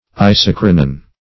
Search Result for " isochronon" : The Collaborative International Dictionary of English v.0.48: Isochronon \I*soch"ro*non\, n. [NL.